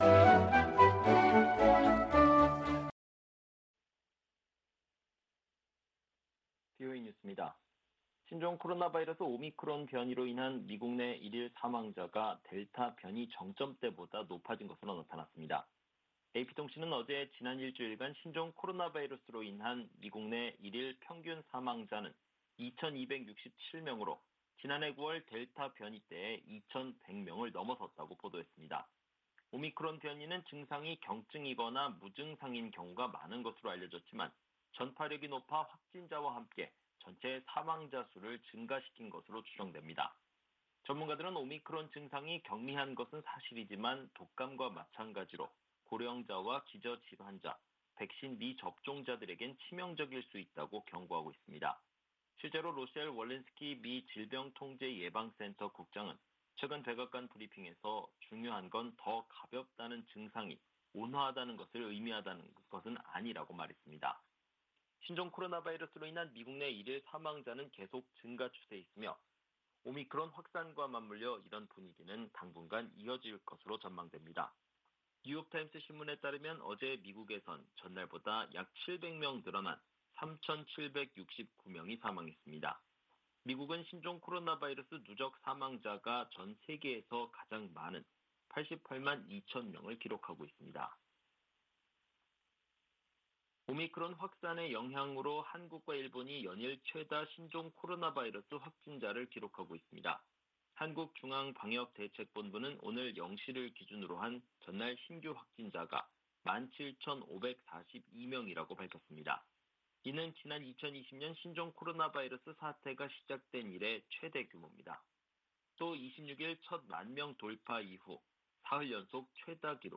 VOA 한국어 방송의 토요일 오후 프로그램 3부입니다.